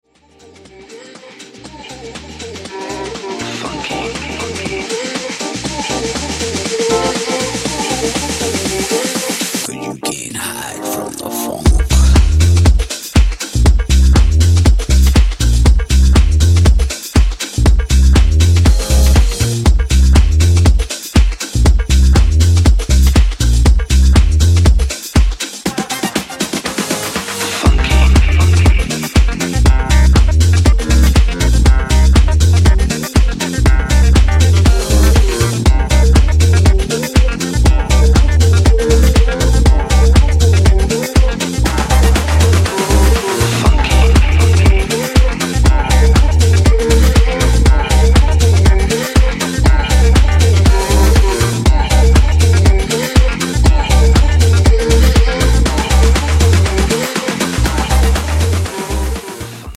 house music single